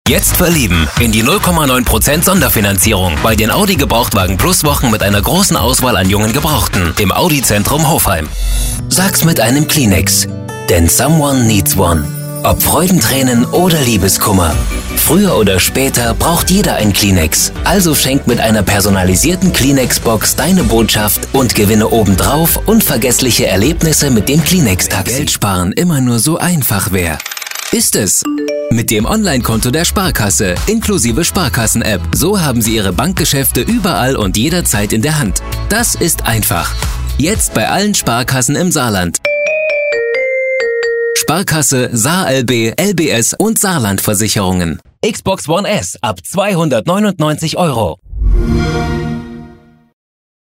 Professioneller Sprecher - Schnelle Bearbeitung - Eigenes Studio
Kein Dialekt
Sprechprobe: Werbung (Muttersprache):
Werbung.mp3